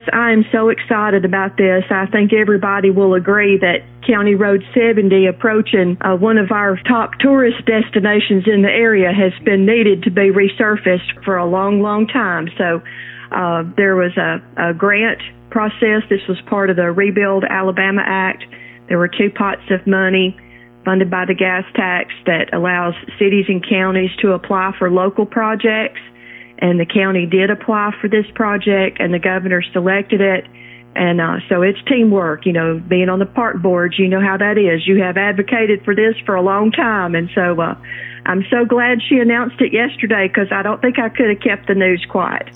District 39 State Representative Ginny Shaver appeared on WEIS Radio early Friday Morning – and shared some great news from the Governor’s Office regarding a much-needed, and very important, step forward for Cherokee County: